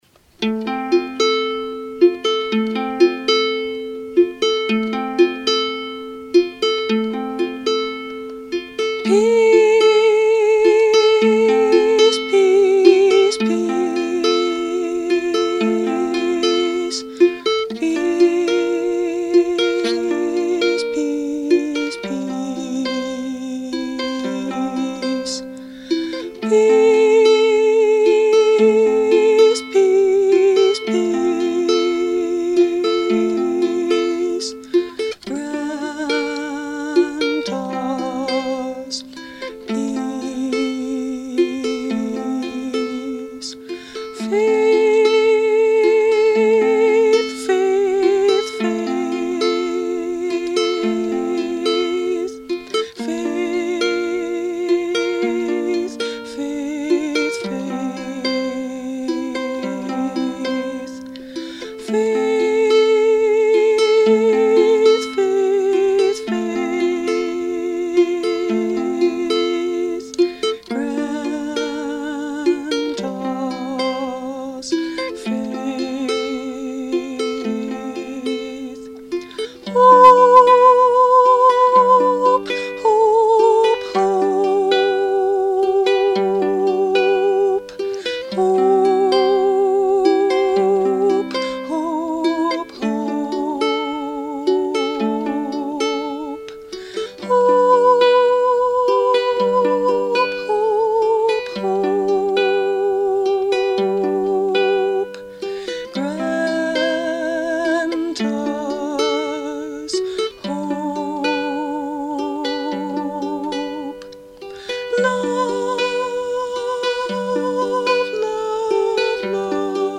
A meditation song for any time and season.
Instrument: Brio – Red Cedar Concert Ukulele